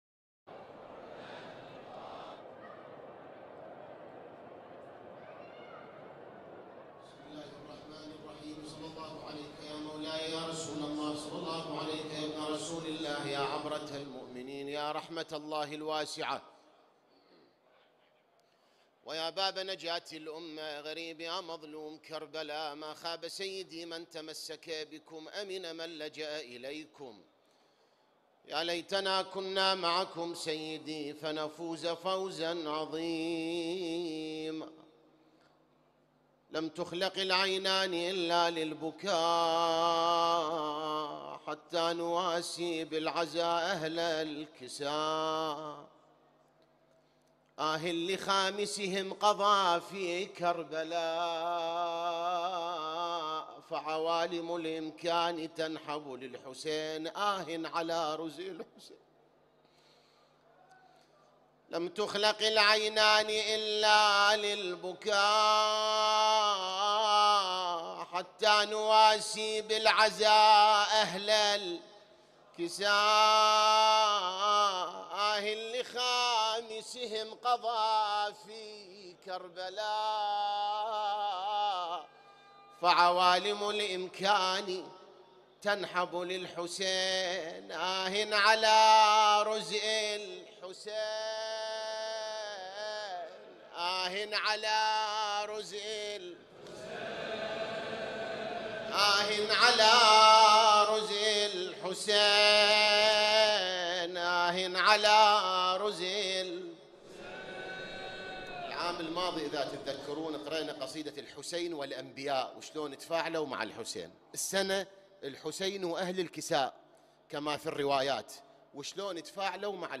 1 محرم 1447 هـ || من الصحن الحسيني